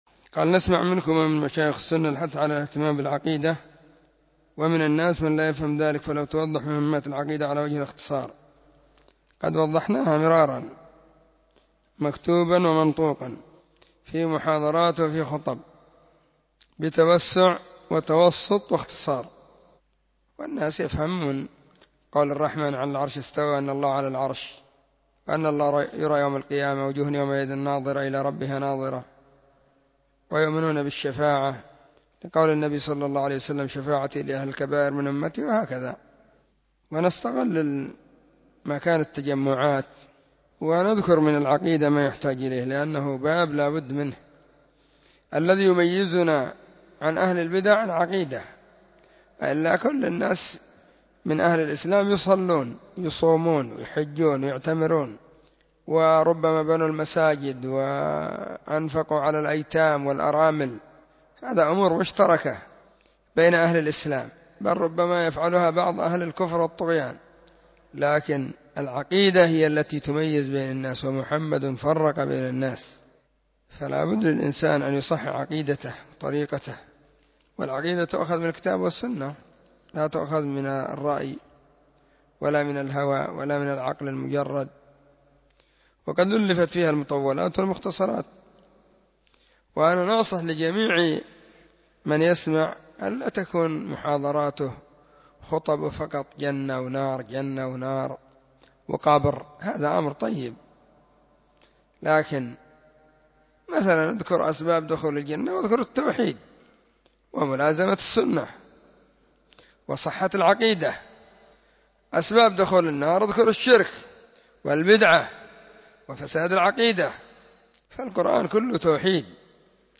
🔸🔹 سلسلة الفتاوى الصوتية المفردة 🔸🔹
📢 مسجد الصحابة – بالغيضة – المهرة، اليمن حرسها الله.